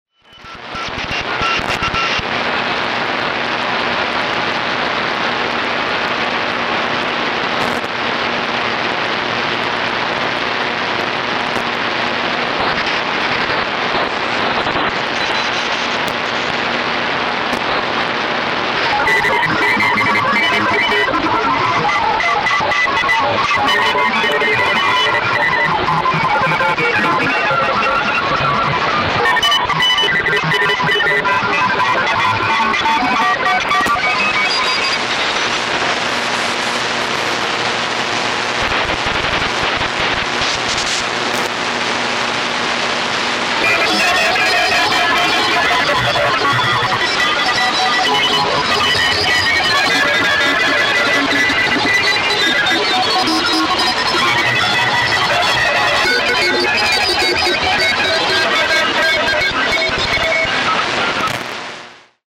Scary Morse Code: This was recorded on the Grundig S350 radio. Average morse code on shortwave in the 7.030 MHz area.